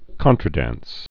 (kŏntrə-dăns)